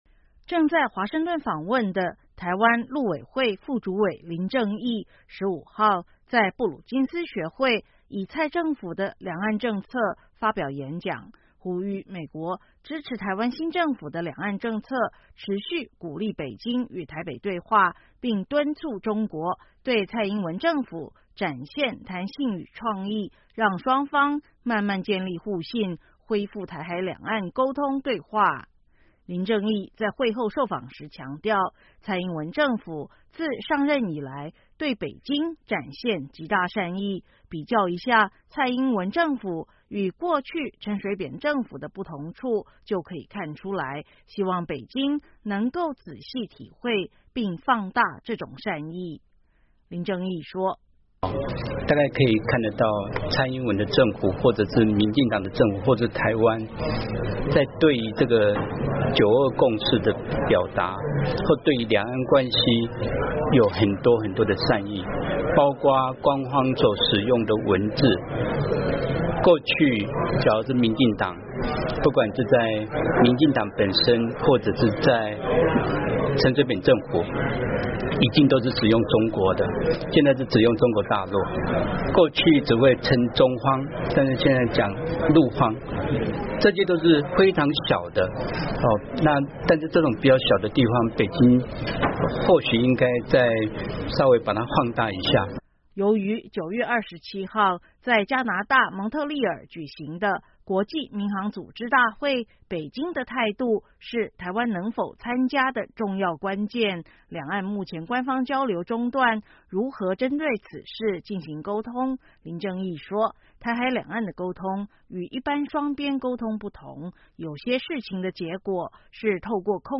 林正义在会后受访时强调，蔡英文政府自上任以来对北京展现极大善意，比较一下蔡英文政府与过去陈水扁政府的不同处就可以看出来，希望北京能够仔细体会并放大这种善意。